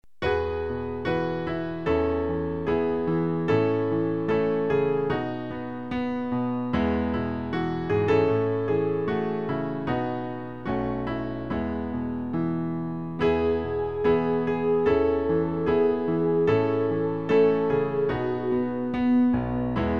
Klavier-Playback zur Begleitung der Gemeinde MP3 Download
Klavier-Playback